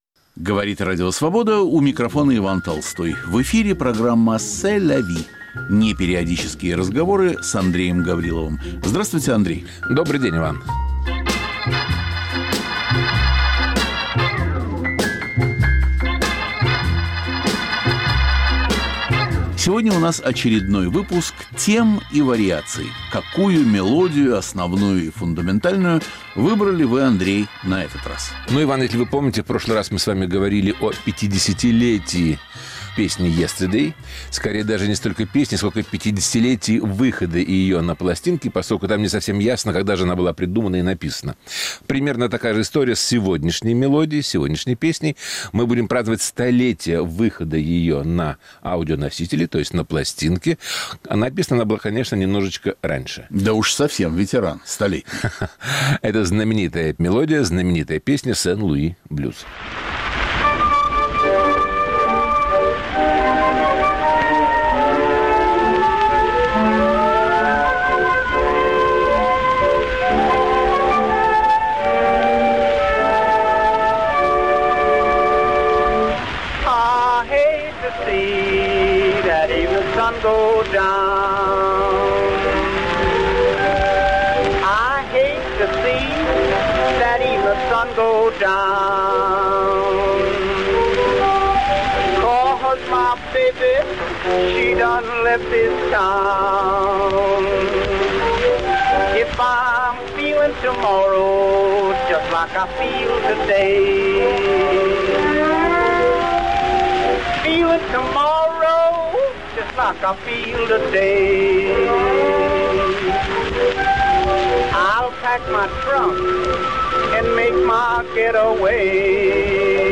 Из истории великих мелодий – «Сен-Луи блюз», к 100-летию создания. Слушайте лучшие исполнения.